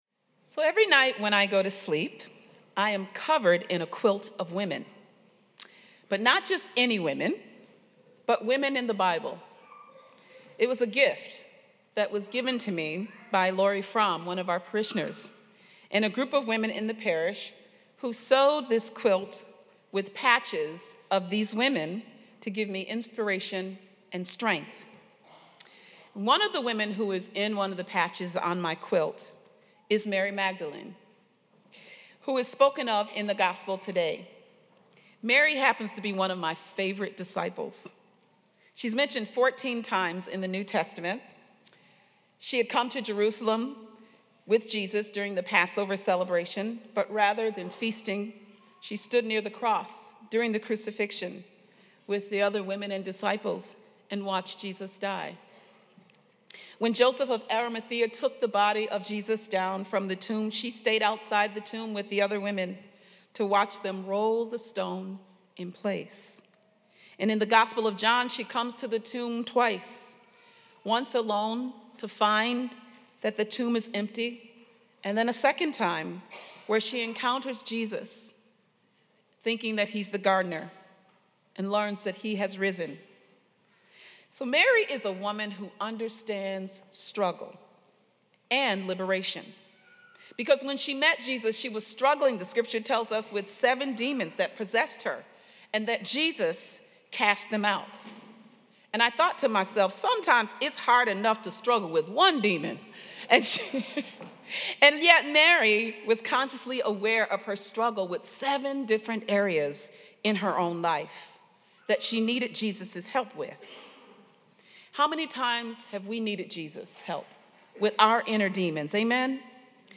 She preached on how Jesus’ unwavering love for us all, brings us from darkness to light, from hopelessness to hope, to being alone and knowing that God was there all the time. Homily Transcript Every night when I go to sleep I am covered in a quilt of women.